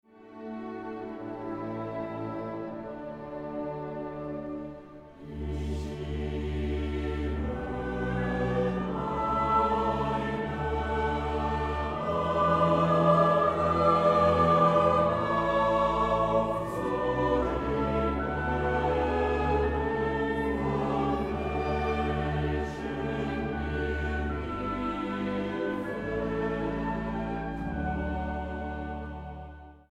Feierstunde in Berlin-Lichtenberg am 6. November 2010